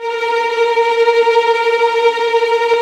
Index of /90_sSampleCDs/Roland LCDP08 Symphony Orchestra/STR_Vls Tremolo/STR_Vls Trem wh%